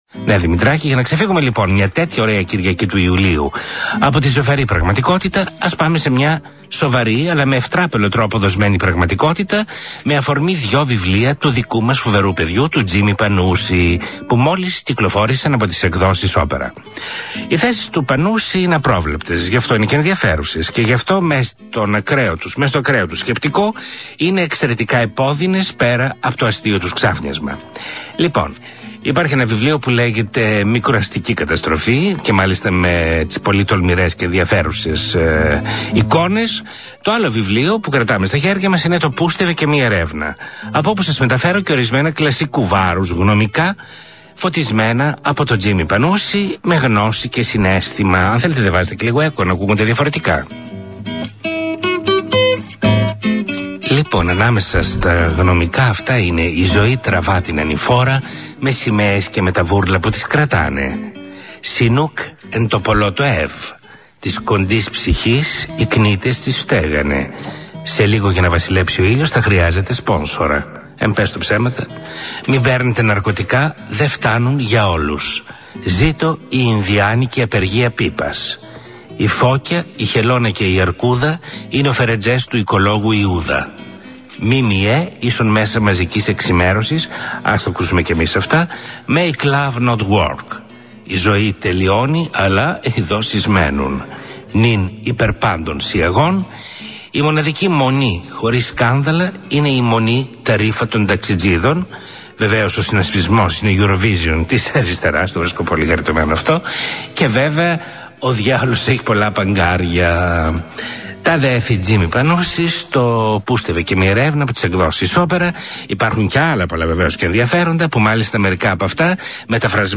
Βιβλιοπαρουσίαση (στην ραδιοφωνική εκπομπή του Γιάννη Ξανθούλη Το Τραμ Περνάει στις 12 για τα βιβλία του Τζίμη Πανούση Μικροαστική Καταστροφή και Πούστευε και Μη Ερεύνα.) 2m 43s 1.09MB